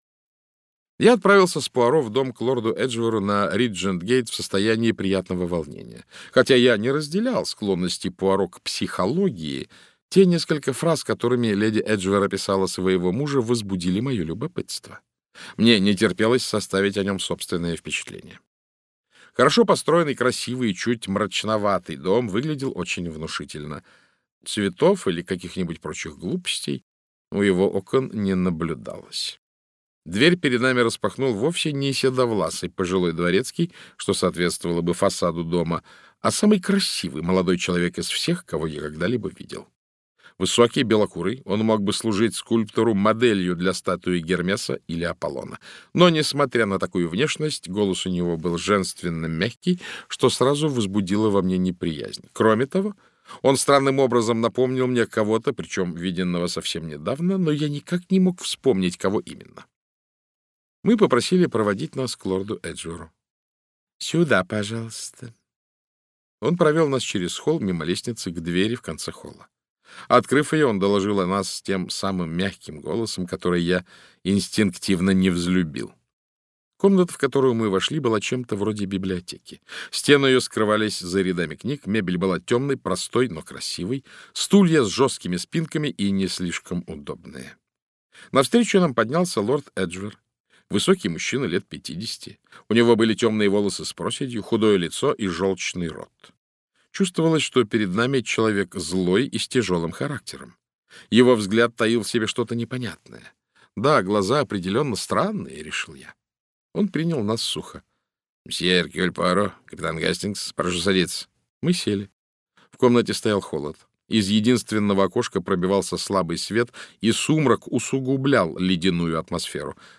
Аудиокнига Смерть лорда Эджвера - купить, скачать и слушать онлайн | КнигоПоиск